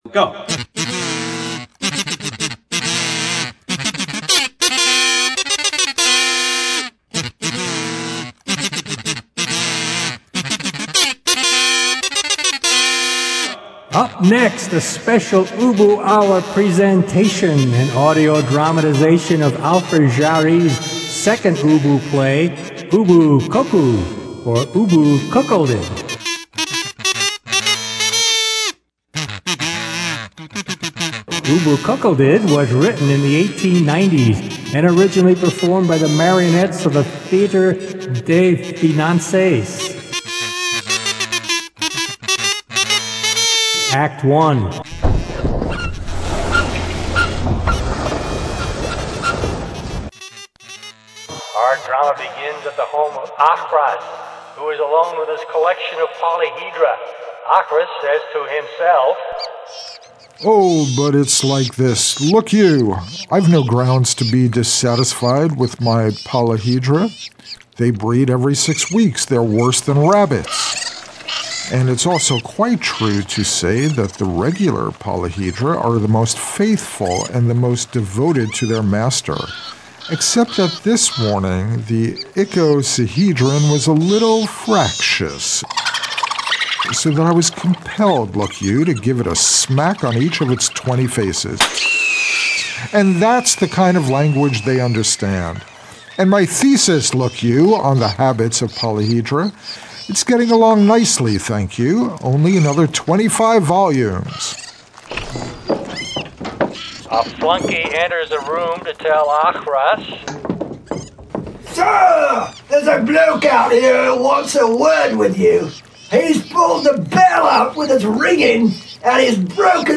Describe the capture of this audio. This aired sometime during the 101 Hour DADA festival on KBOO in 2008,